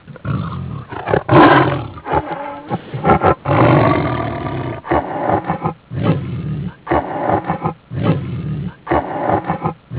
SES-ÖTÜŞÜ
Tipik çağrısı testere sesine benzer ve 8-10 kere arka arkaya tekrarlanır.
leopard.mp3